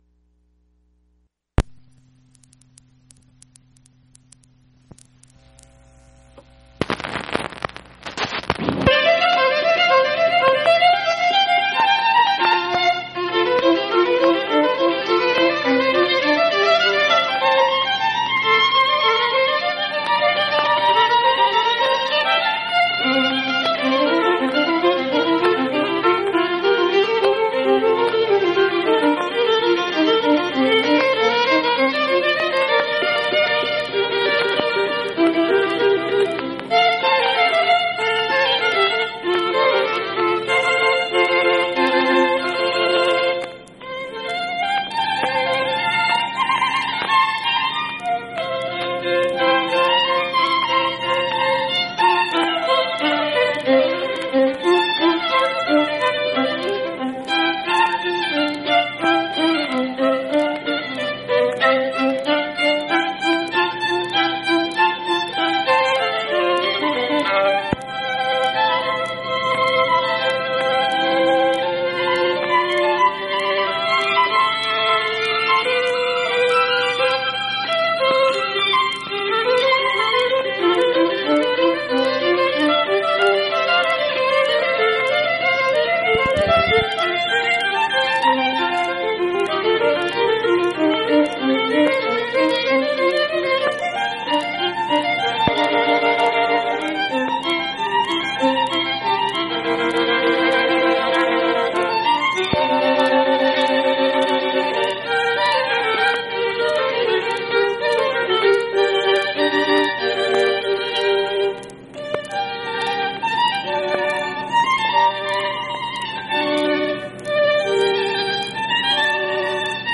Leclair: Sonata No. 4 in F major for Two Violins, Op. 3, No. 4
violins